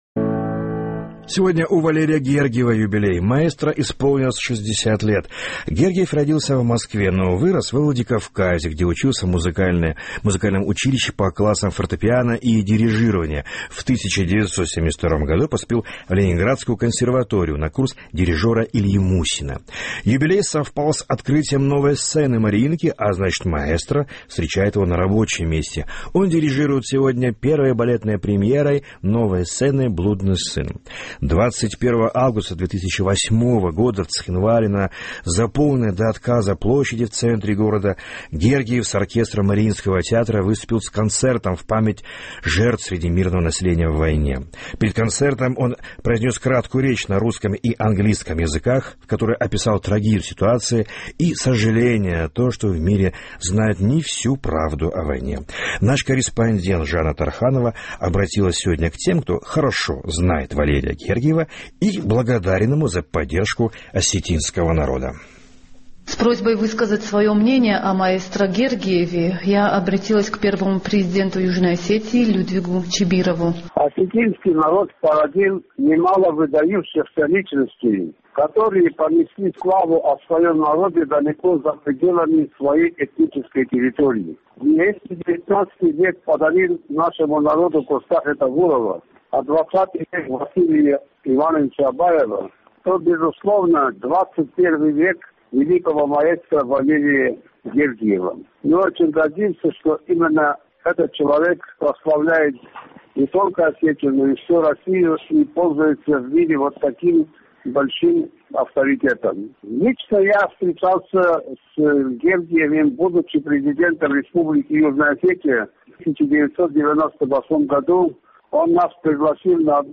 21 августа 2008 года в Цхинвале, на заполненной до отказа площади в центре города Валерий Гергиев с оркестром Мариинского театра выступил с концертом в память жертв среди мирного населения в войне.